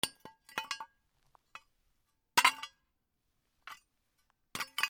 金属の小片 ぶつける 物音
/ M｜他分類 / L01 ｜小道具 / 金属
『チャカチャ』